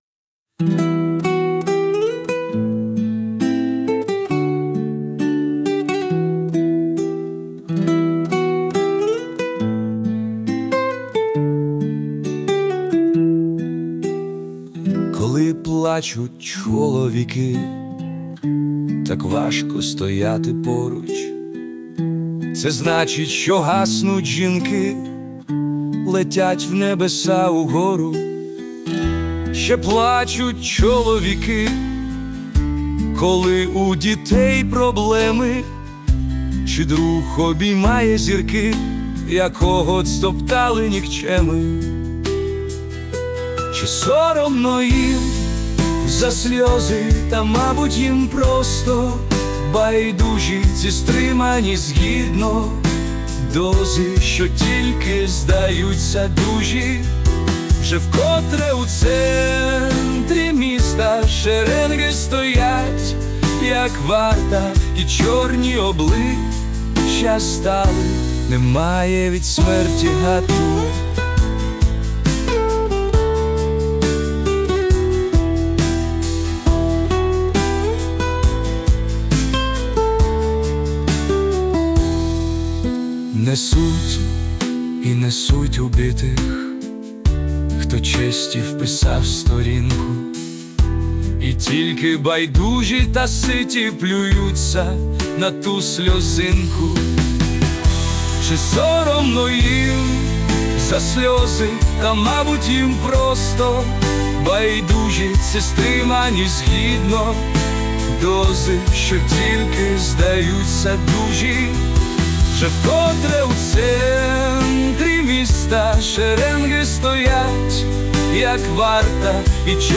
Музичний супровід створено з допомогою ШІ